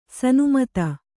♪ sanumata